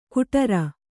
♪ kuṭara